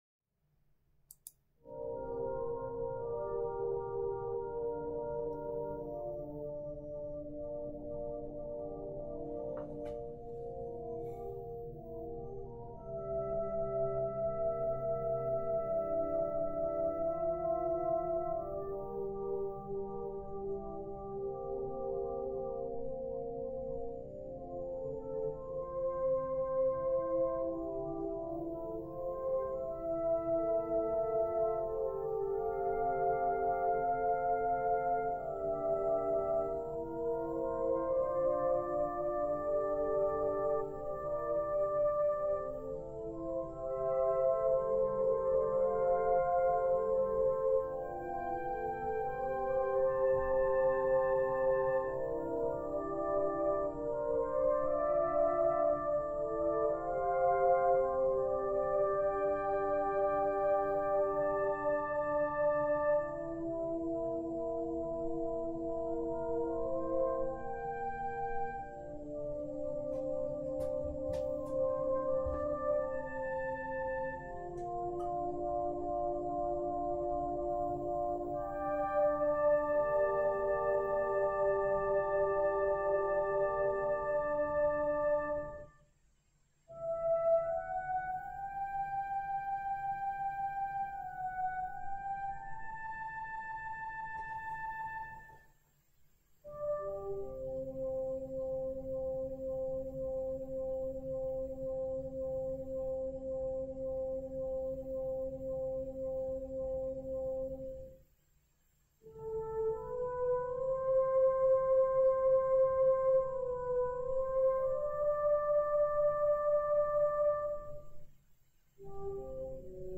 meter = "Largo"